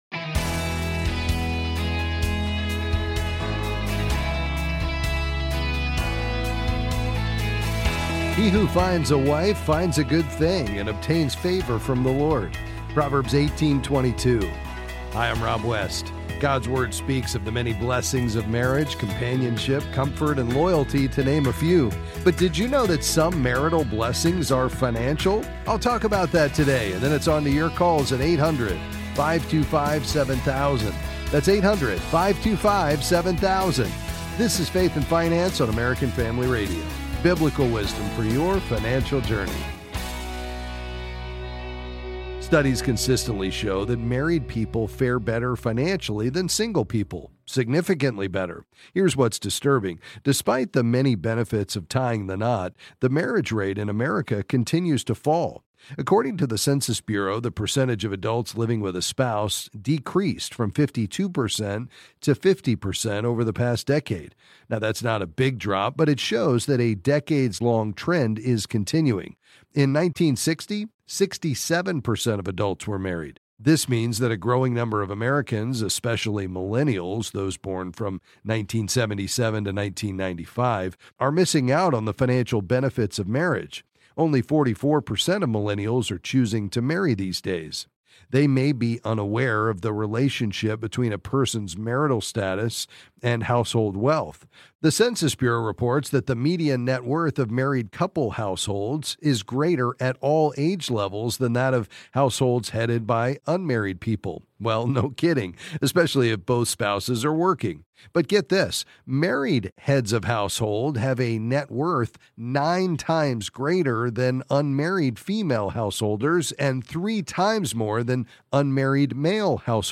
Then he takes your calls and various financial questions.